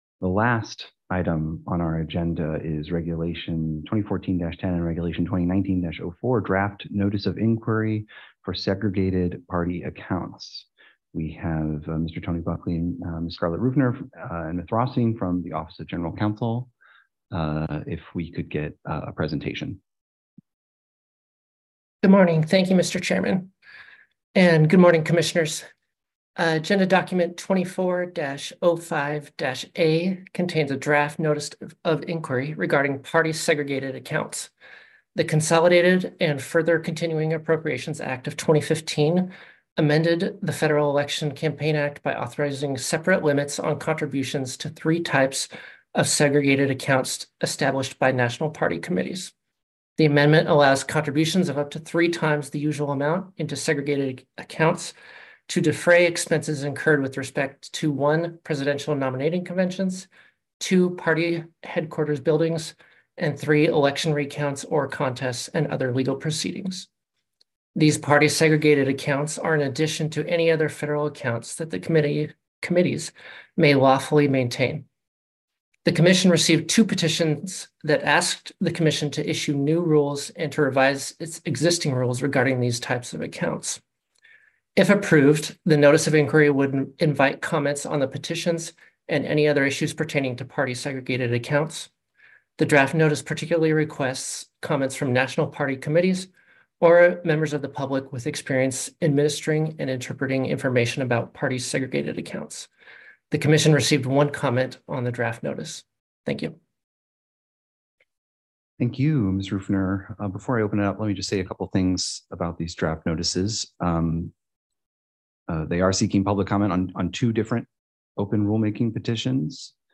February 8, 2024 open meeting